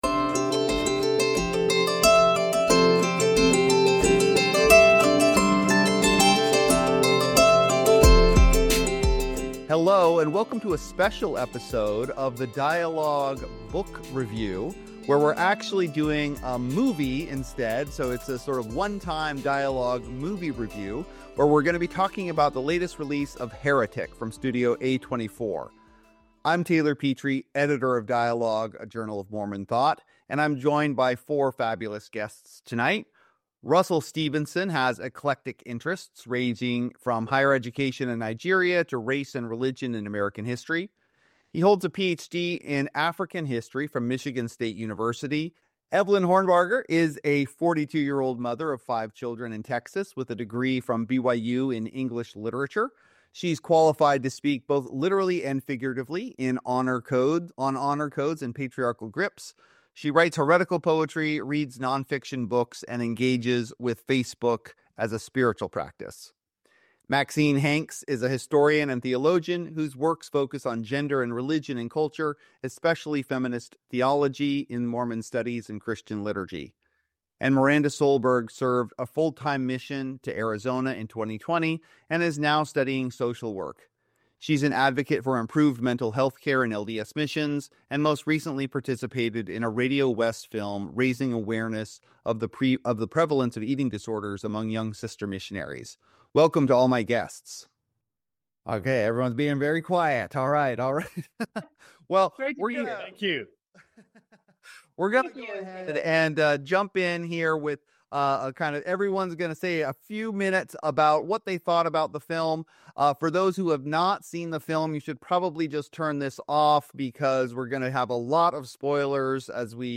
leads a panel of scholars and advocates to unpack themes of Mormon horror, faith, and rebellion.